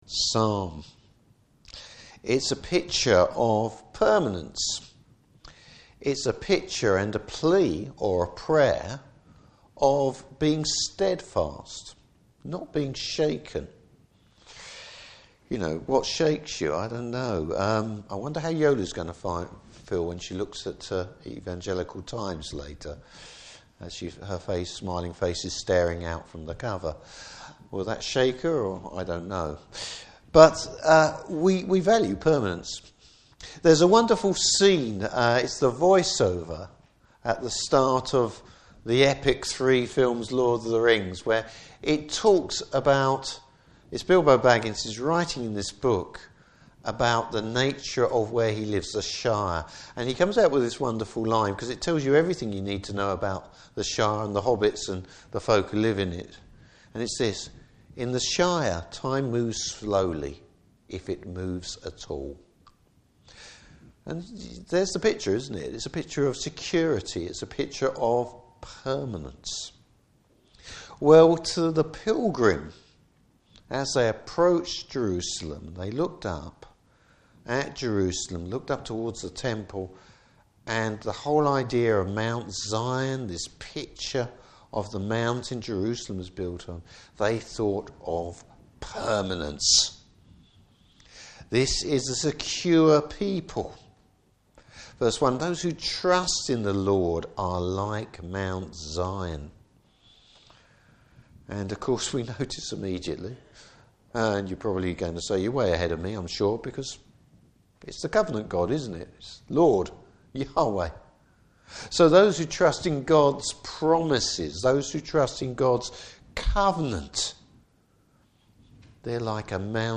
Service Type: Evening Service Bible Text: Psalm 125.